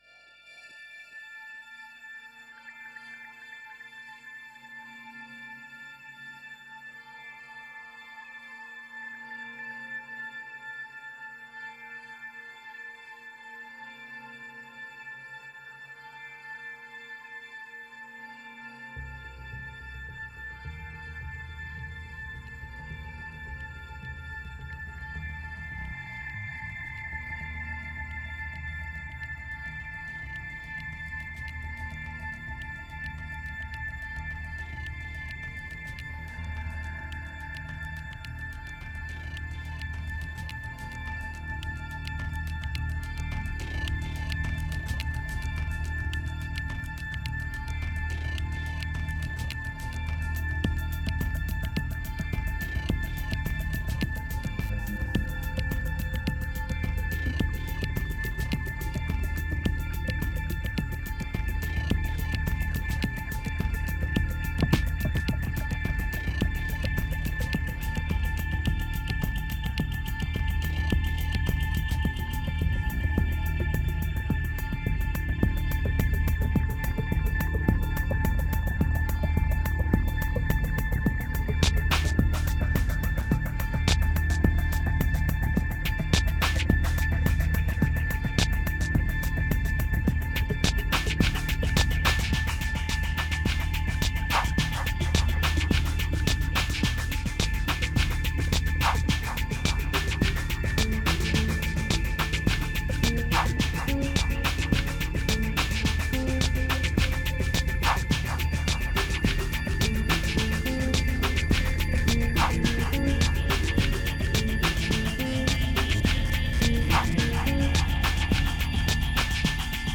2450📈 - 20%🤔 - 107BPM🔊 - 2011-01-26📅 - -89🌟